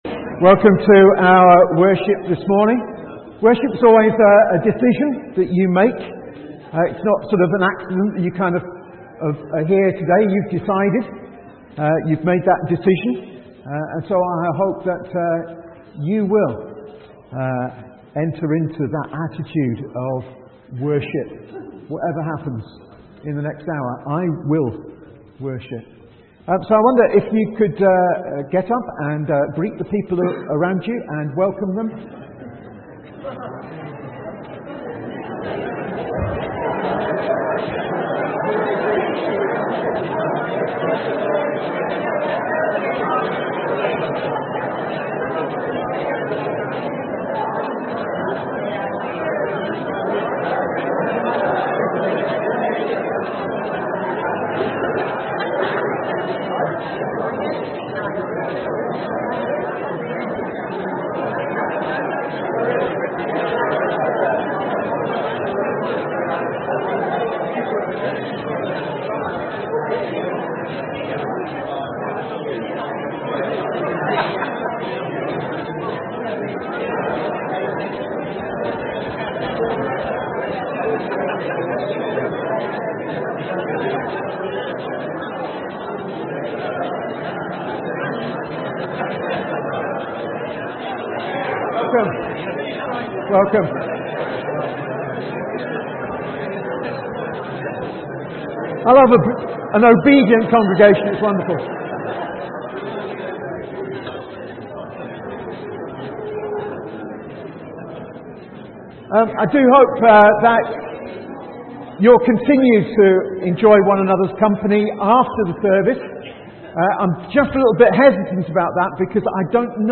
From Service: "10.45am Service"